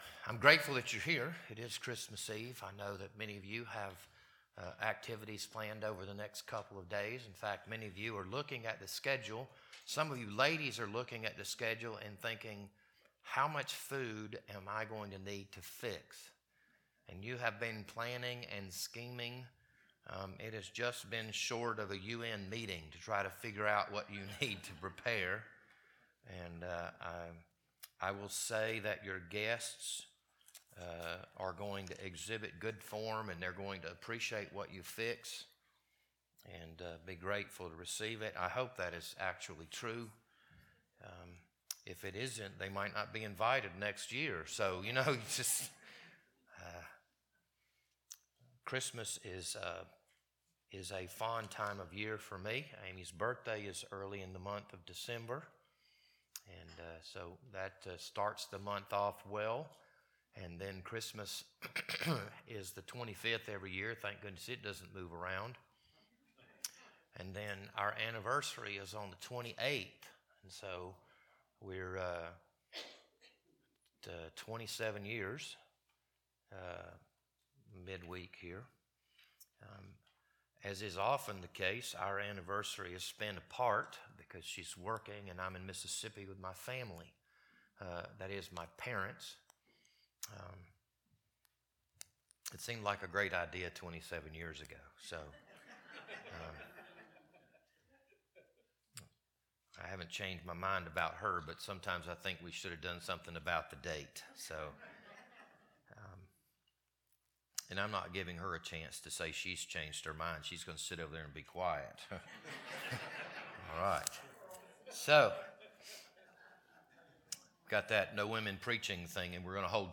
This Sunday morning sermon was recorded on December 24th, 2023.